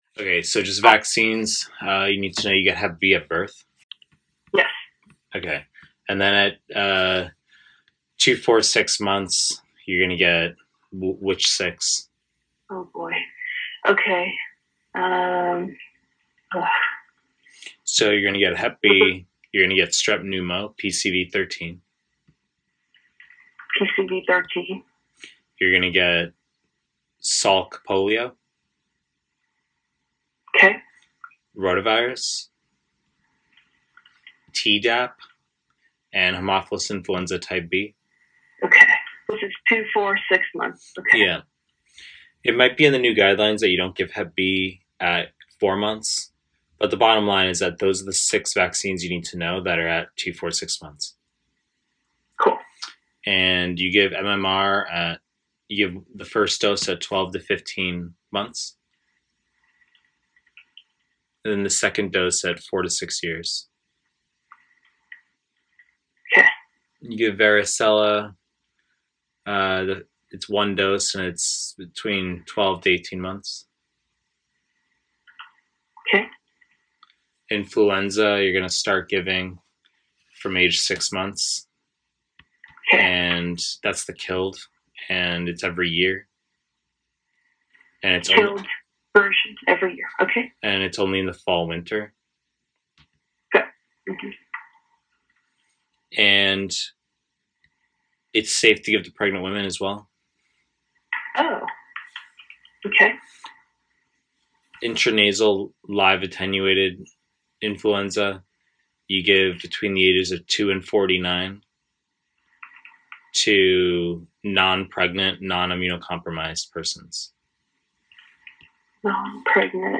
Family medicine / Pre-recorded lectures